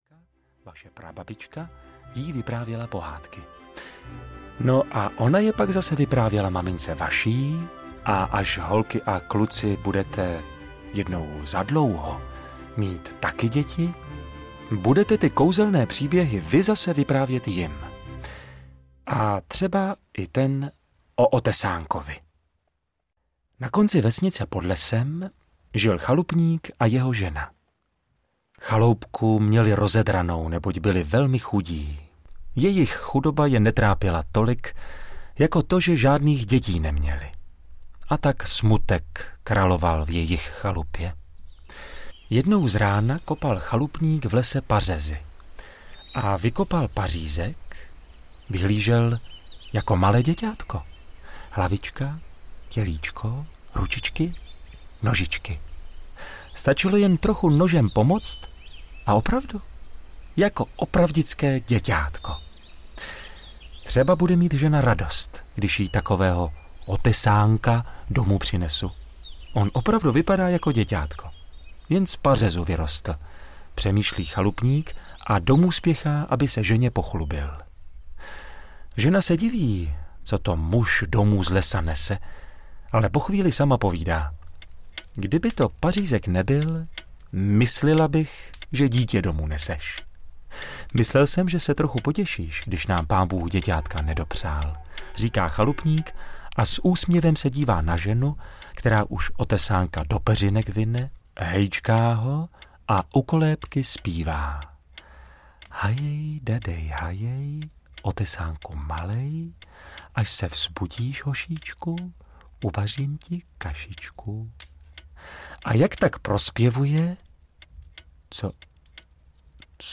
21 nejhezčích českých pohádek audiokniha
Ukázka z knihy